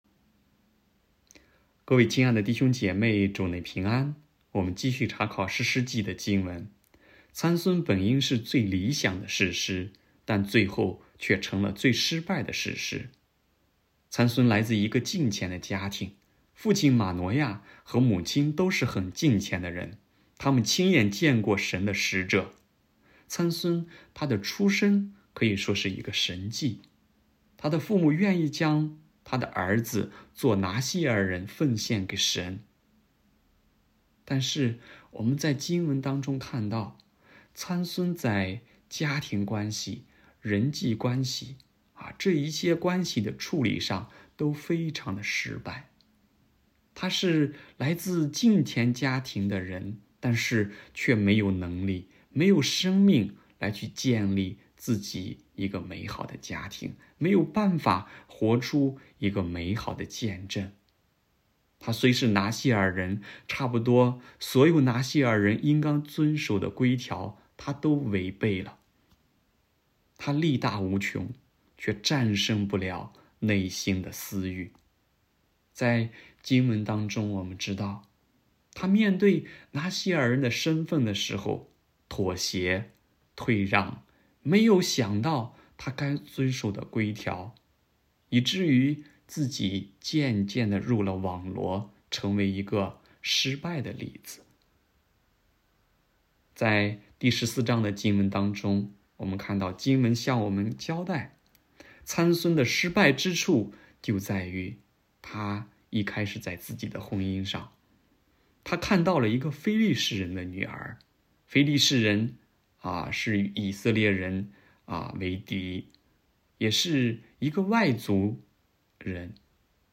北京基督教会海淀堂